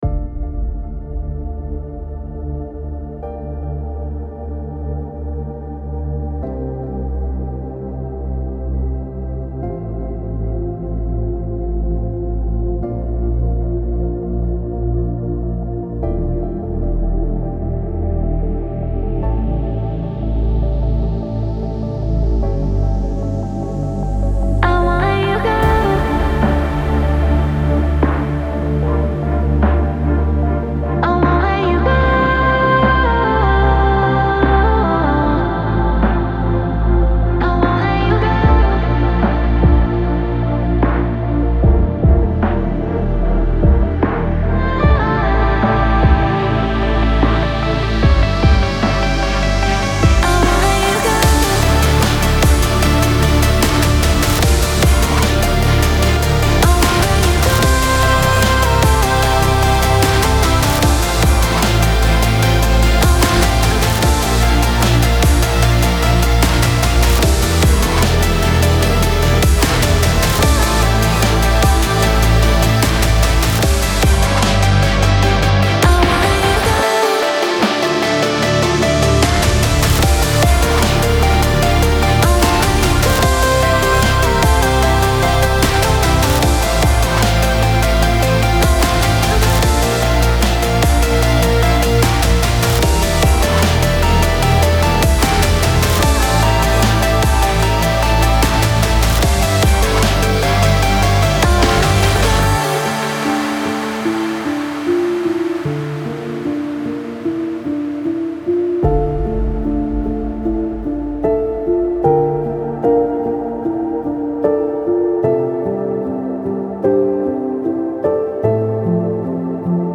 موسیقی کنار تو
موسیقی بی کلام چیل تریپ